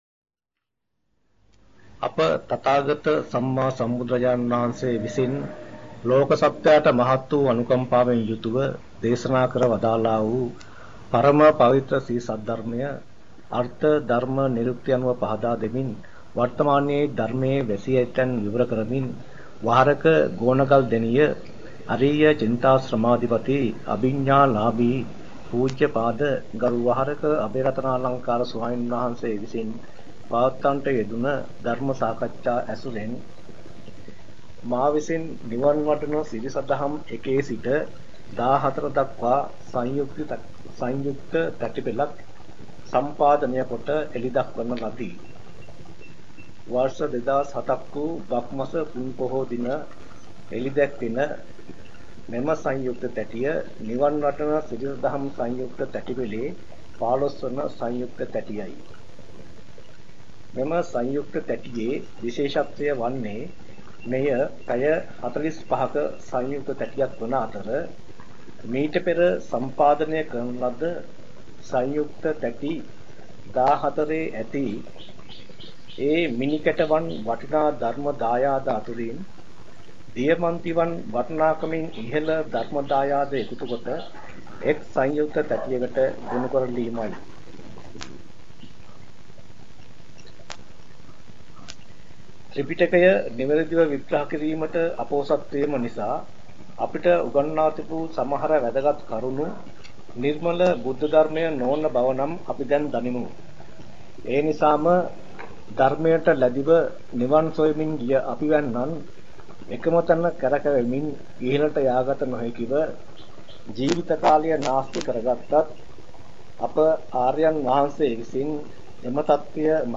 වෙනත් බ්‍රව්සරයක් භාවිතා කරන්නැයි යෝජනා කර සිටිමු 03:47 10 fast_rewind 10 fast_forward share බෙදාගන්න මෙම දේශනය පසුව සවන් දීමට අවැසි නම් මෙතැනින් බාගත කරන්න  (2 MB)